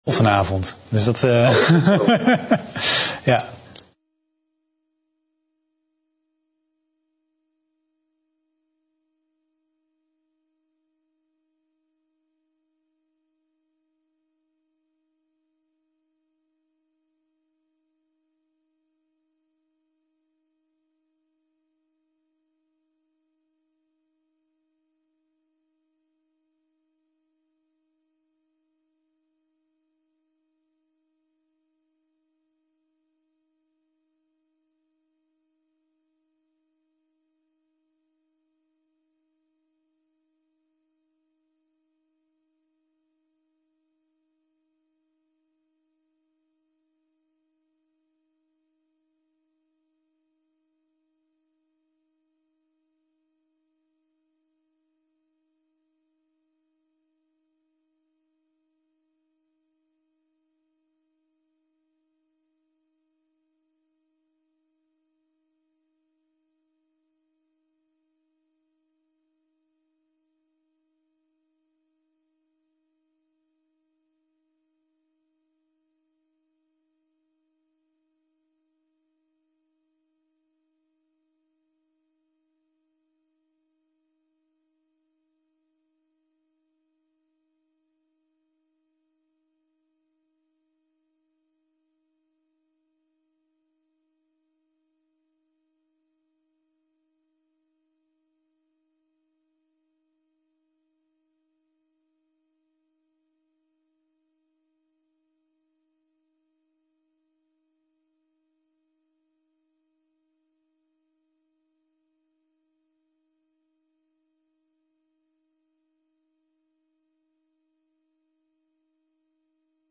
De vergadering wordt digitaal gehouden gezien de aangescherpte maatregelen.